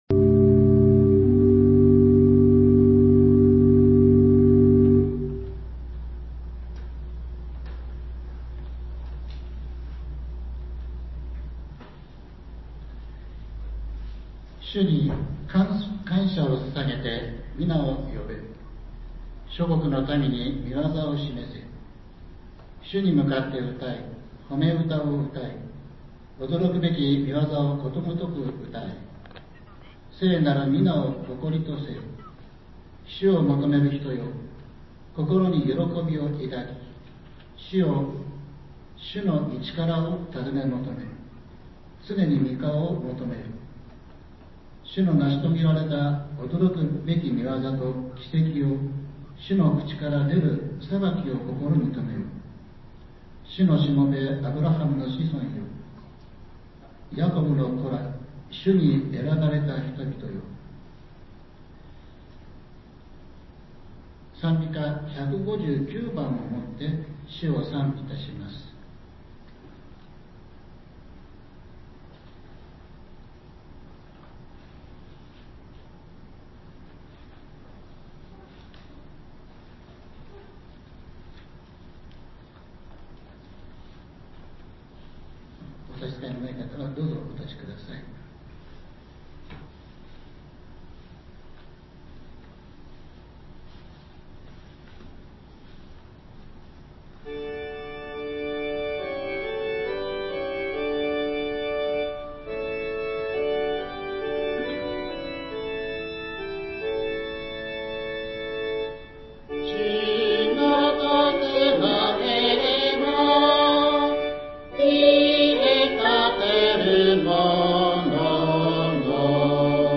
１月２５日（日）主日礼拝